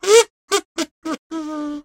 Звуки казу
Откройте для себя завораживающие звуки казу – музыкального инструмента с необычным тембром.
Казу музыкальный инструмент интересные факты